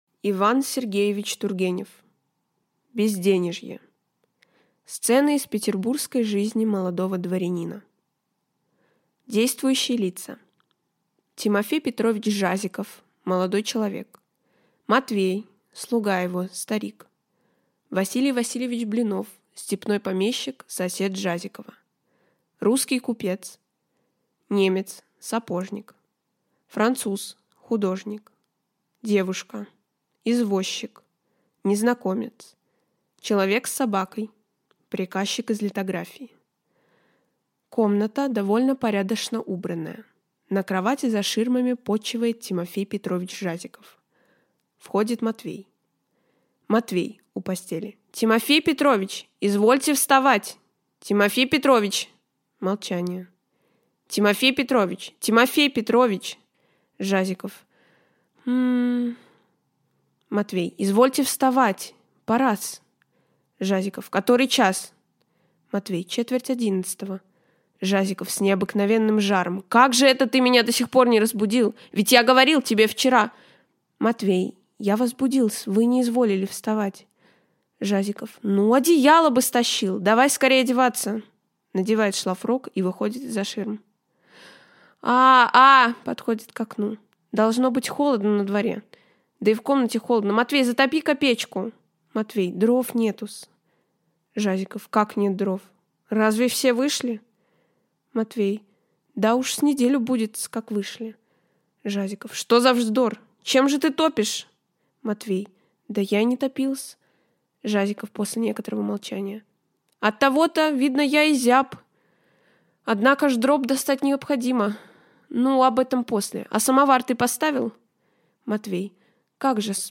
Аудиокнига Безденежье | Библиотека аудиокниг